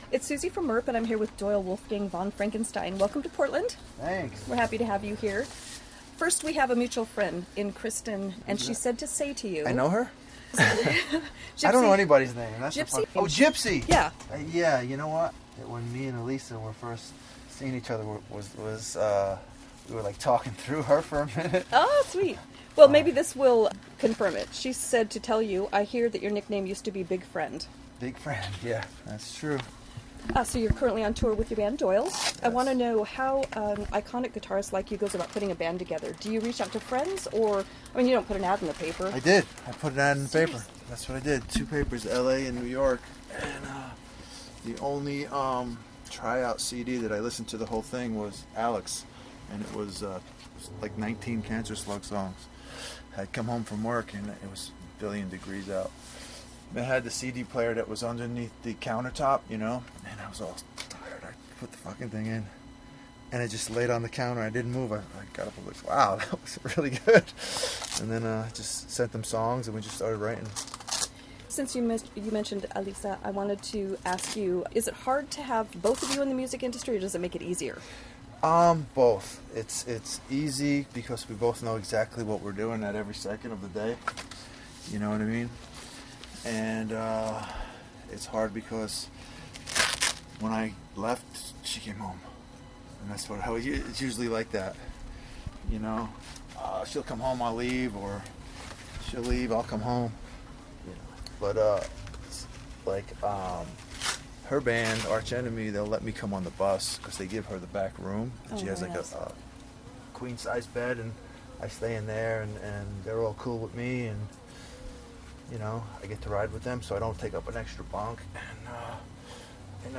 Interview with Doyle Wolfgang von Franenstein - Portland, Oregon - 2018 | MIRP
doyle-interview.mp3